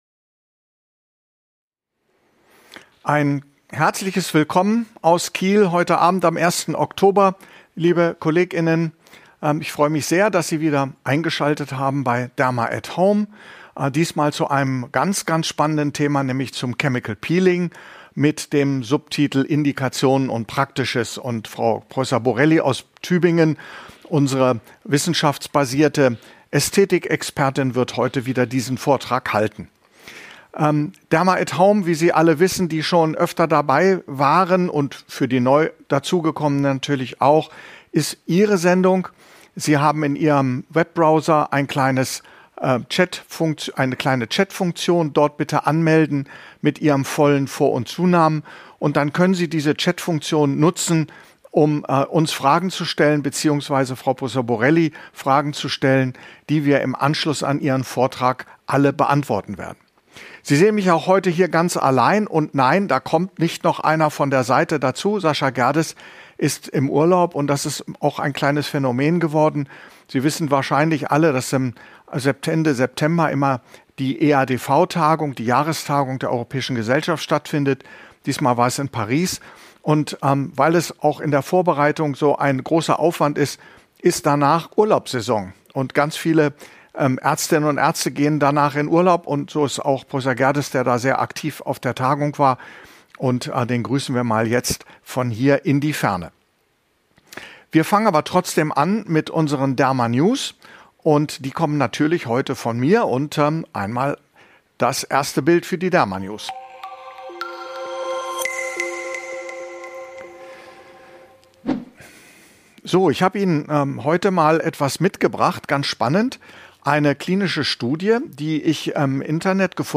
In dieser Episode referiert die Expertin